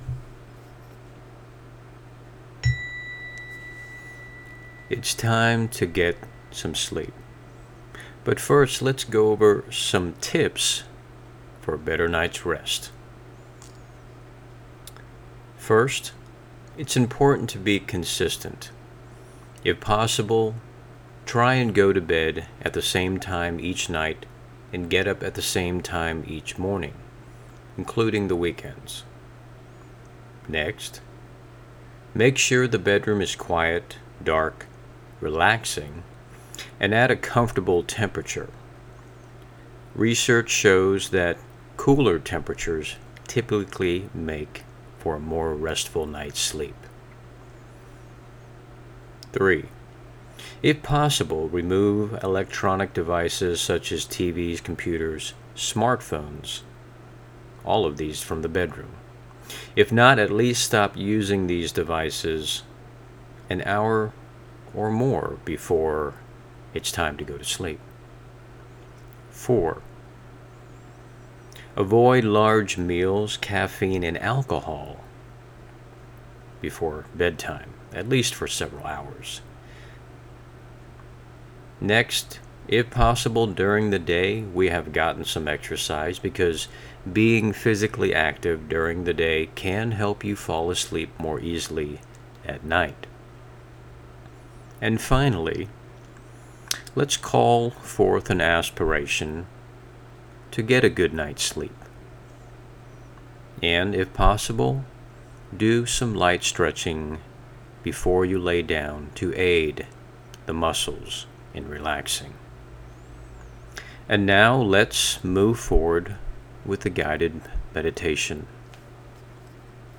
Overcoming short-term insomnia can be relieved with a combination of strategies and pre-bedtime rituals. The Sleep Hacks offered by ARC Nutra can be complemented by incorporating the pre-bedtime rituals provided by one of ARC’s meditation practitioners.